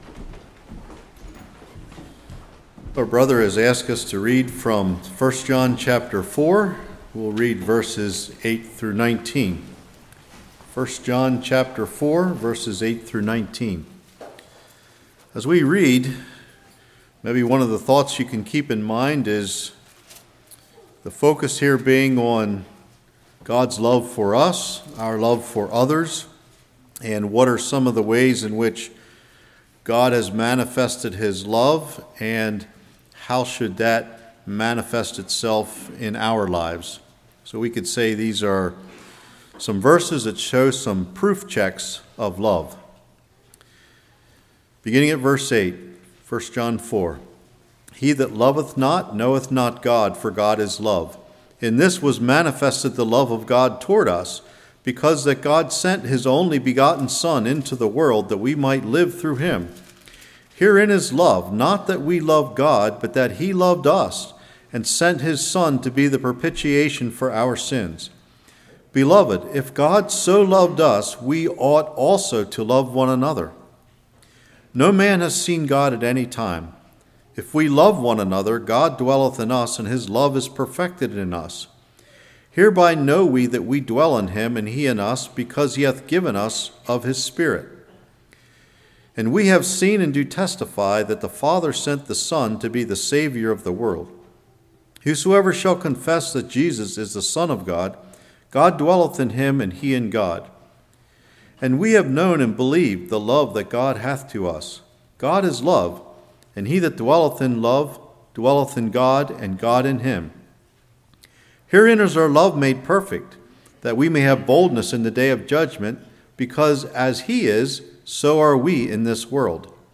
1 John 4:8-21 Service Type: Morning Are You Loyal To Jesus?